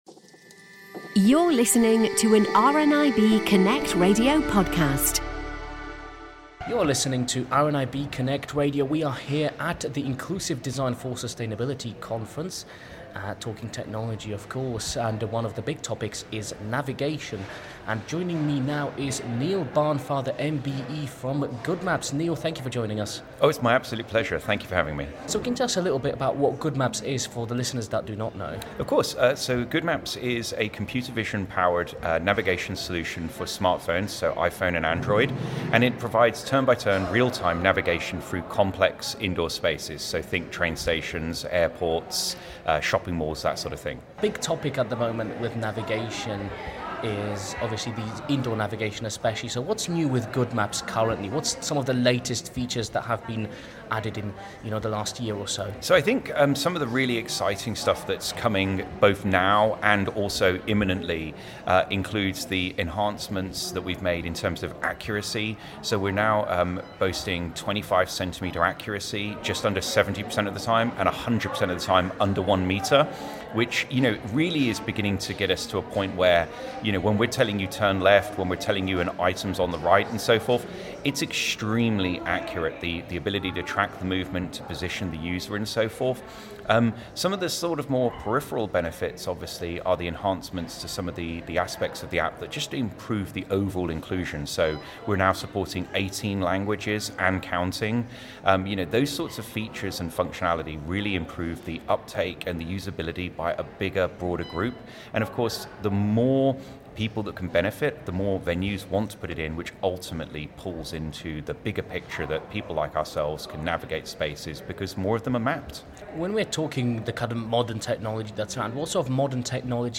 Going back to the inclusive design for sustainability conference in Glasgow